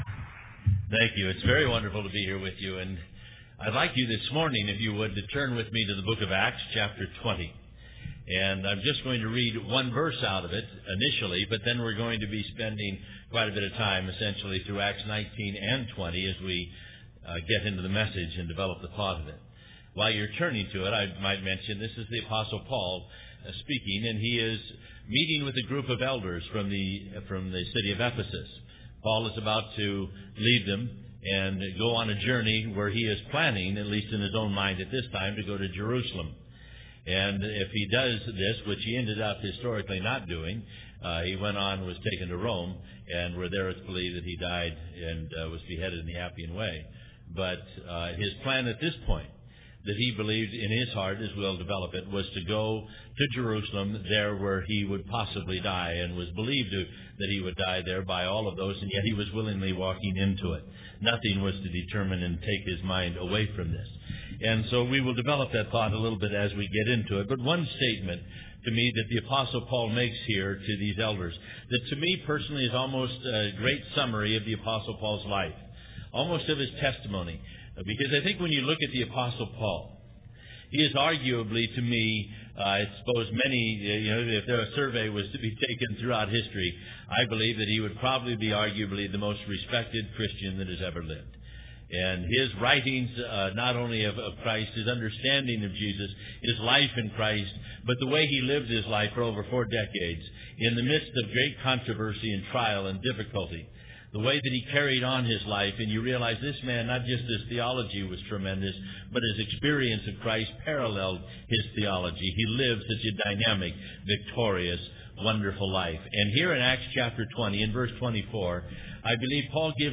Genre: Teaching.